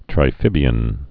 (trī-fĭbē-ən)